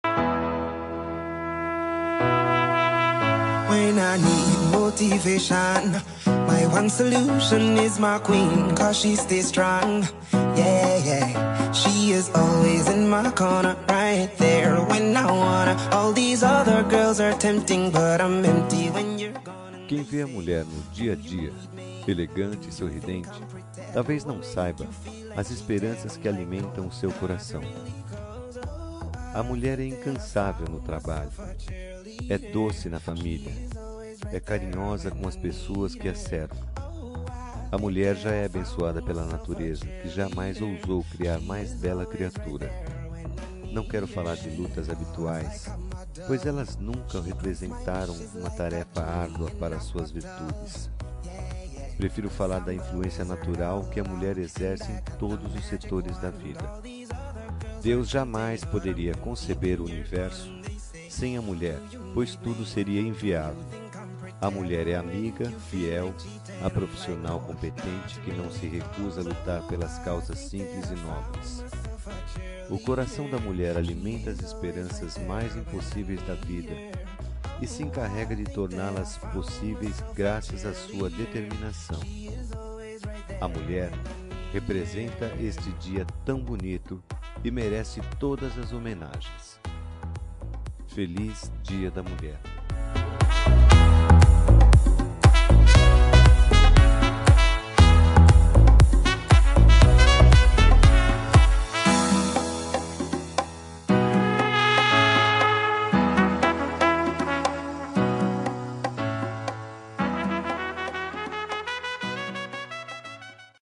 Dia das Mulheres Neutra – Voz Masculina – Cód: 5284